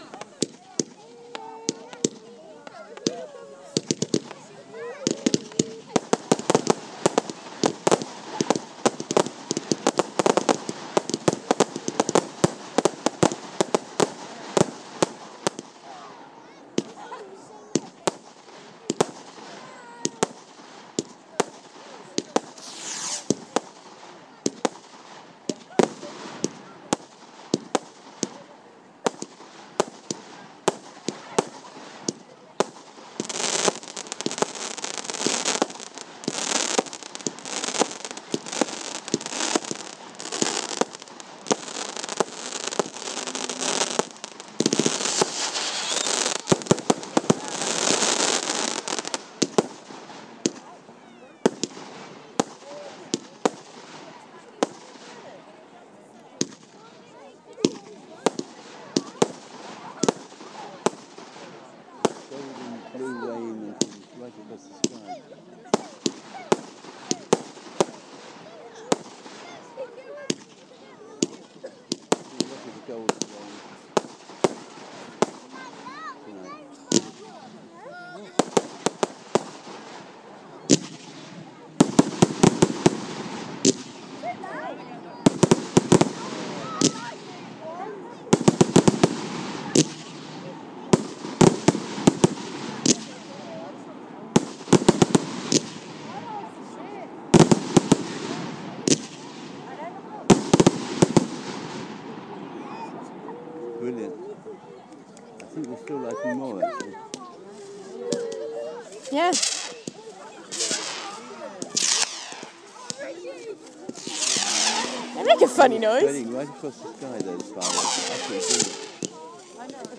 Amazing Pershore Fireworks part 3